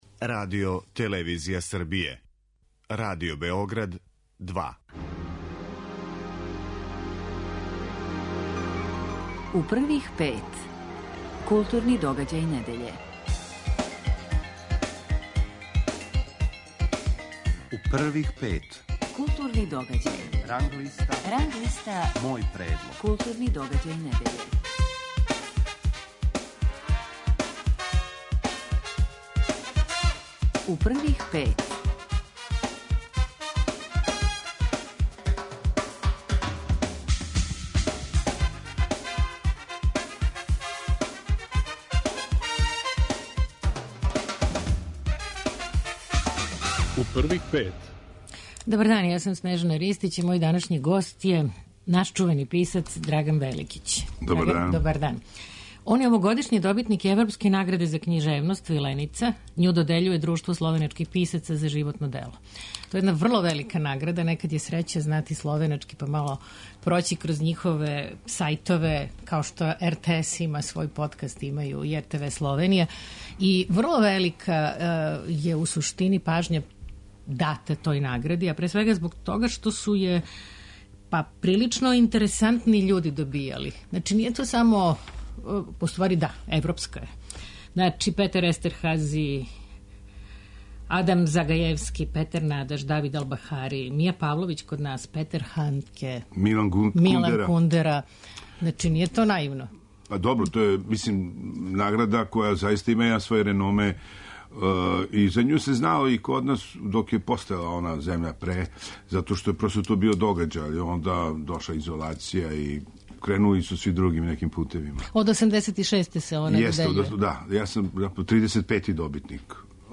Гост је наш чувени писац Драган Великић. Он је овогодишњи добитник Европске награде за књижевност Vilenica коју додељује Друштво словеначких писаца за животно дело.